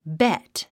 発音
bét　ベェット